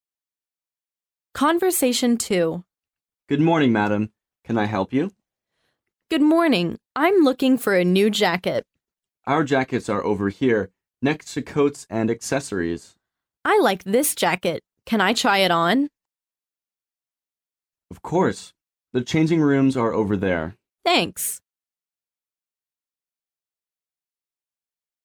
Conversation 2